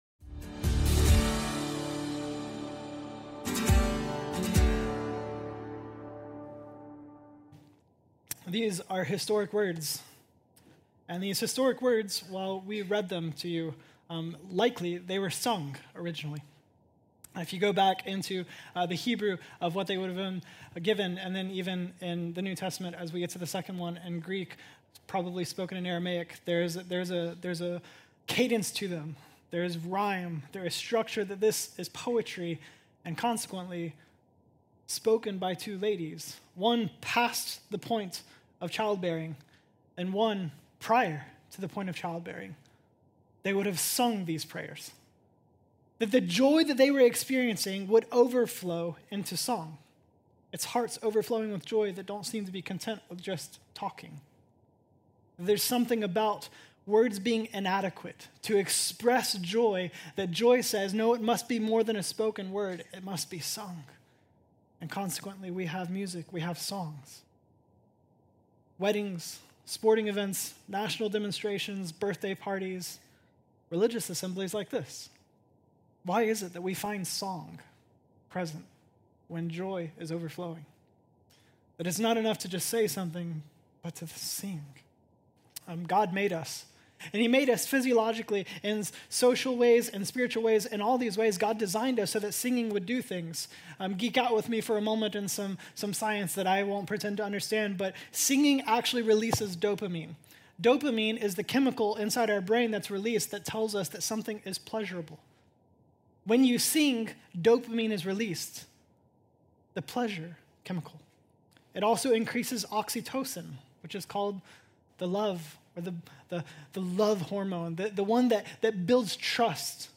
Series: ADVENT